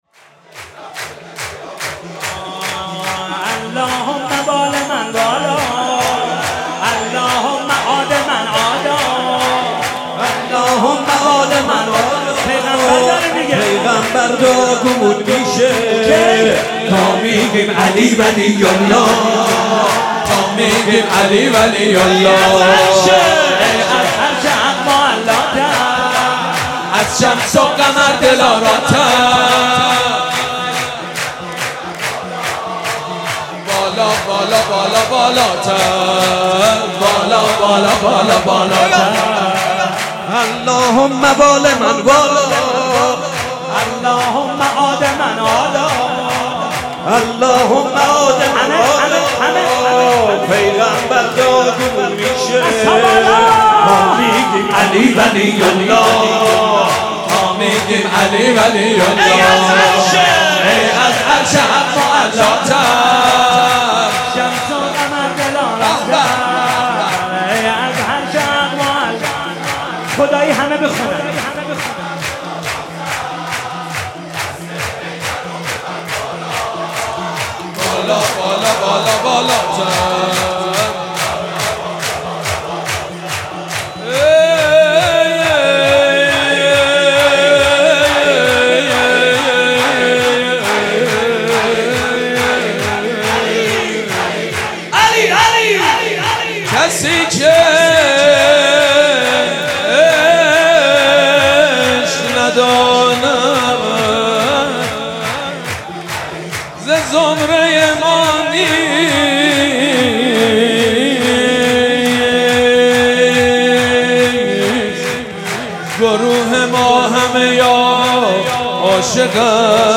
مداحی به سبک شور (جشن) اجرا شده است.